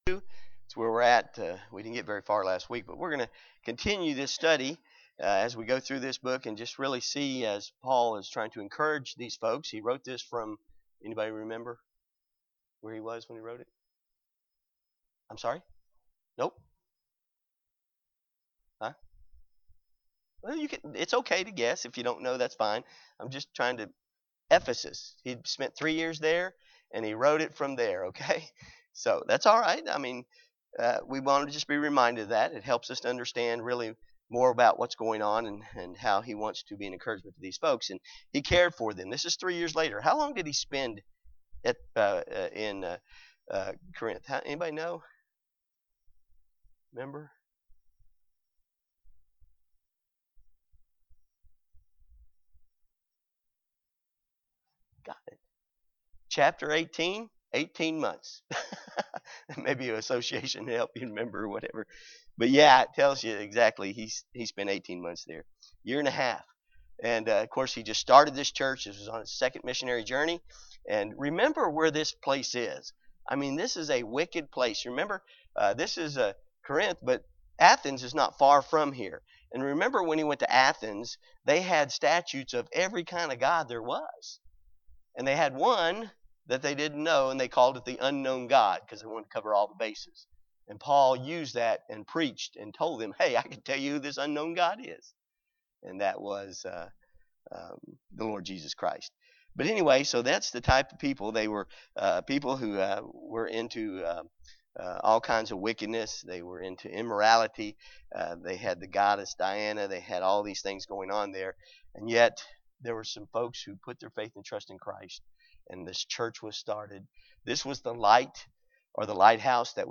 2020 A Study in the Book of 1st Corinthians Passage: 1st Corinthians 2:5-13 Service Type: Sunday School